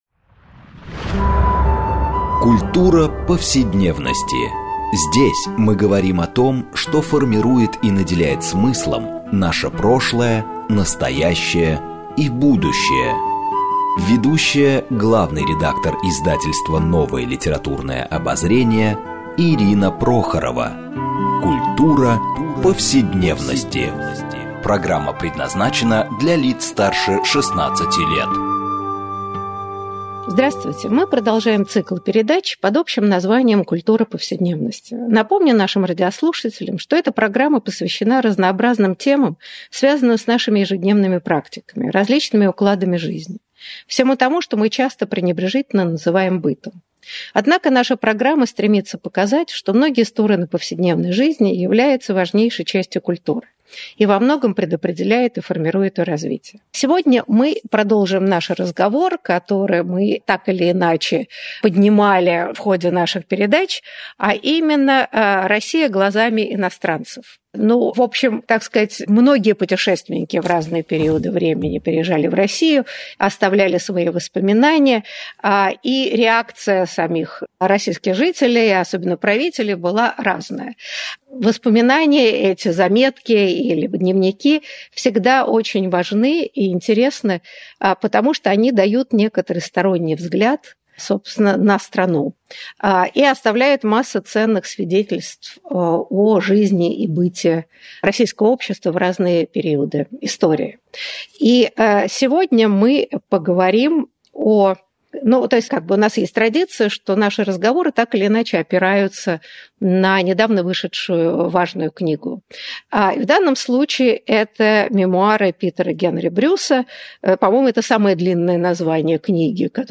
1 Богатые, аморальные и генномодифицированные в Papaya Hospital №233 в Papaya Hospital №233 45:39 Play Pause 5d ago 45:39 Play Pause נגן מאוחר יותר נגן מאוחר יותר רשימות לייק אהבתי 45:39 Привет, в эфире самая китайская разговорная передача, Papaya Hospital!